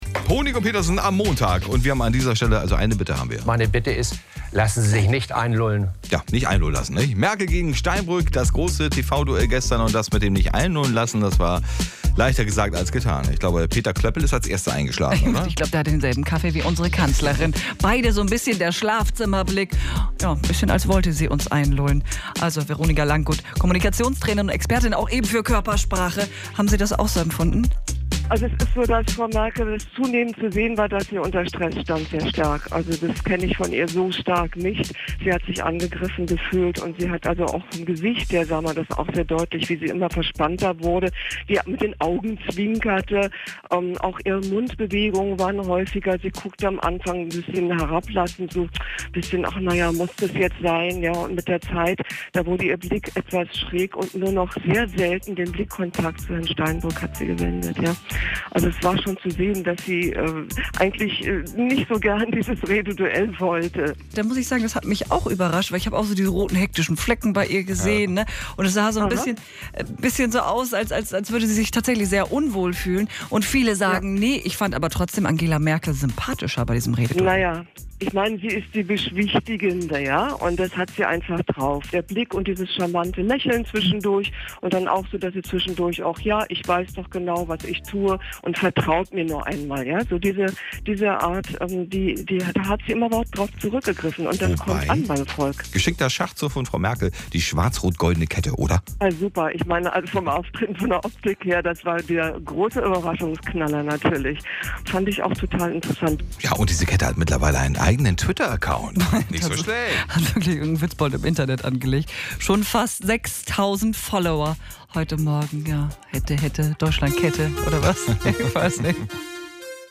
02.09.2013 Radio-Interview: Der NDR 2 Morgen